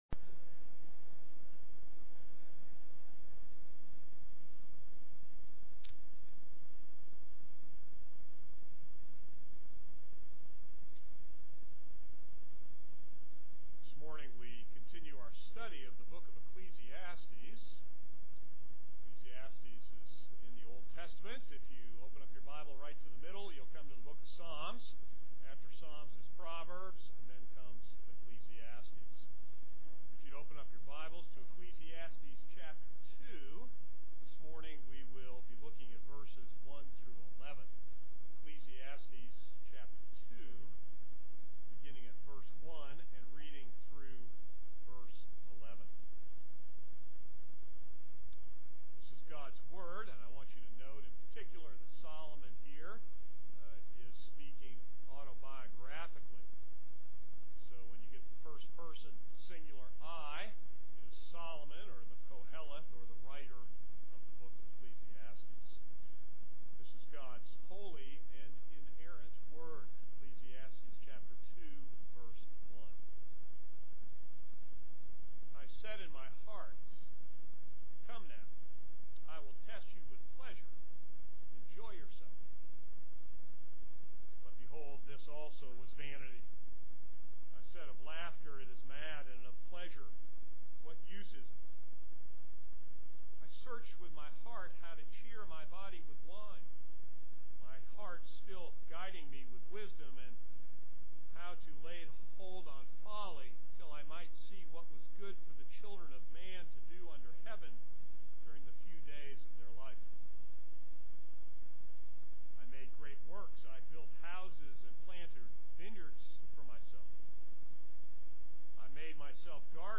This is a sermon on Ecclesiastes 2:1-11.